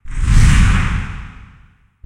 PixelPerfectionCE/assets/minecraft/sounds/mob/enderdragon/wings4.ogg at mc116
wings4.ogg